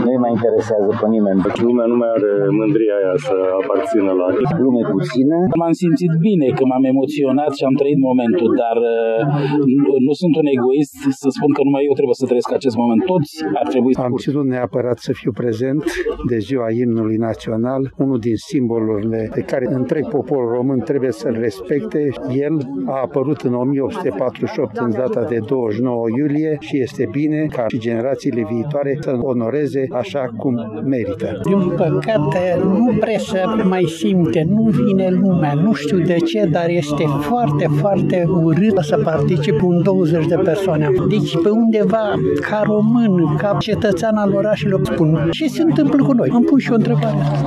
În afară de oficialități și de două plutoane militare, extrem de puțini târgumureșeni au fost prezenți la ceremonialul de pe platoul din fața Teatrului Național din Tg.Mureș: